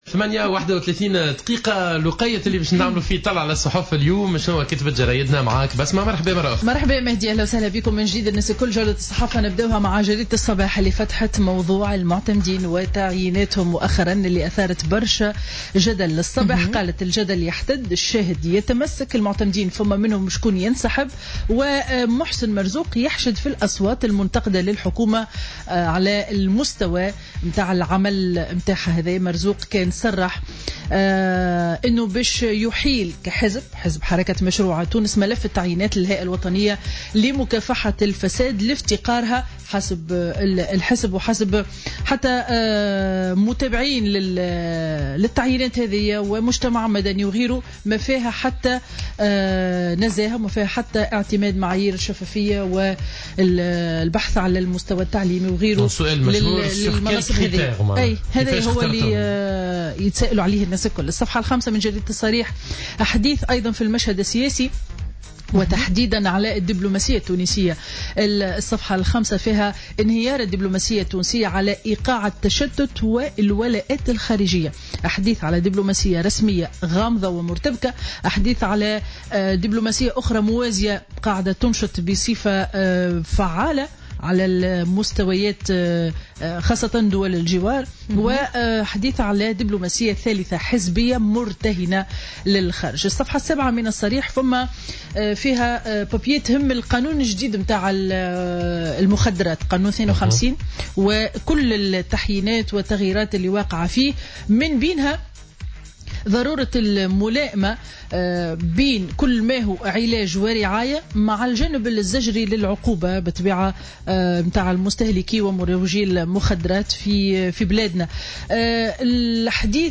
Revue de presse du mercredi 1er février 2017